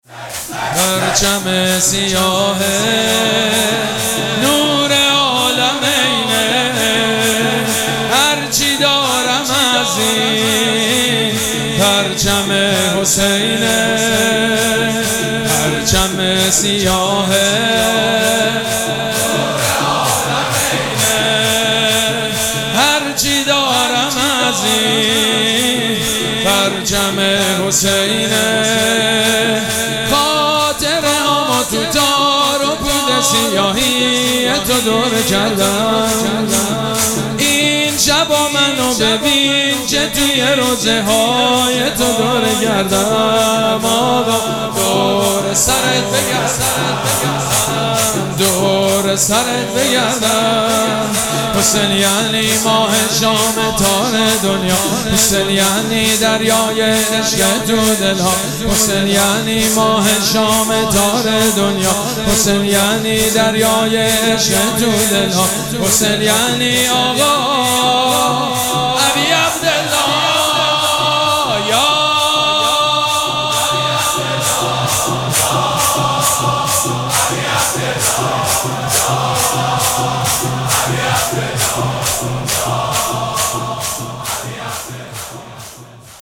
مراسم عزاداری شب هشتم محرم الحرام ۱۴۴۷
شور
حاج سید مجید بنی فاطمه